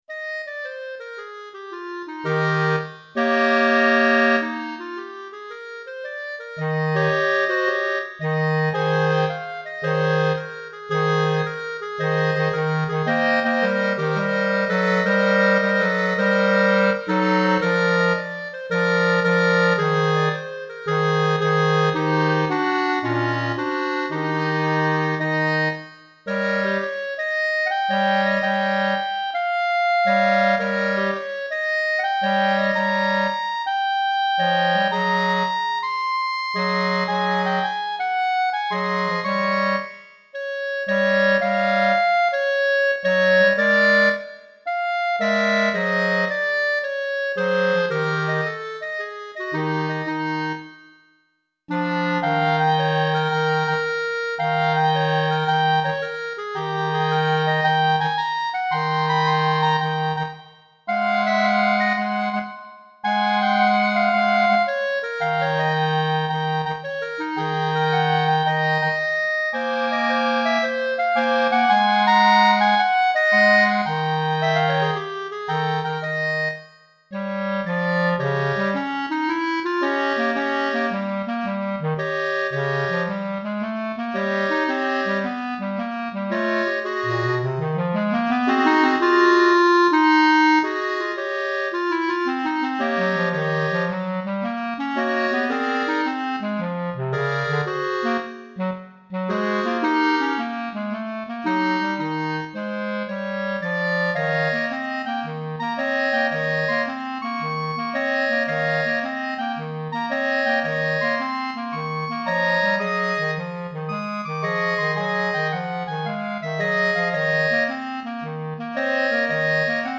3 Clarinettes Sib et Clarinette Basse